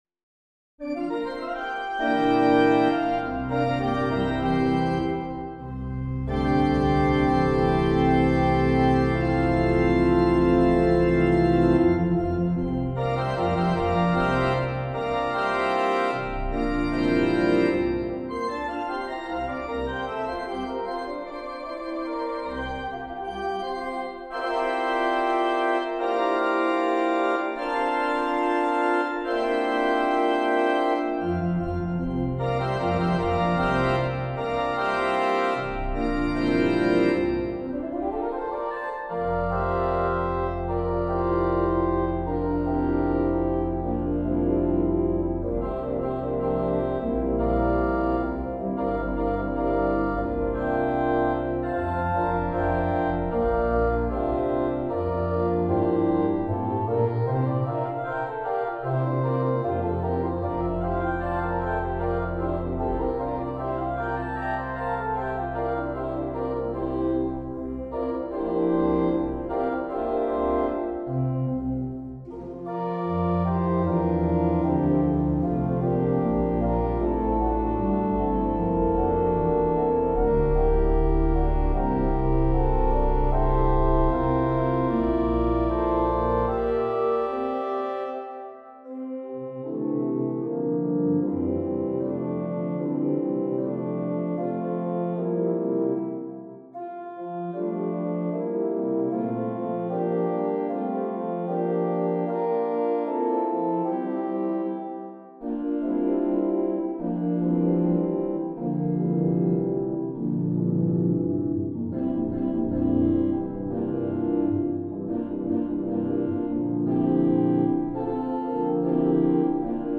for organ
From fantasia to a a fauxbourdon , and from major to minor, the work progresses, changing mood. The parallelism echoes from the early periods through to the impressionists and beyond. A fugato , not a fully formed fugue, then again changes the mood and mode, minor back to major.
The fandango dance rhythm then shifts the mood again, in a very loose song form.